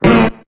Cri de Ronflex dans Pokémon HOME.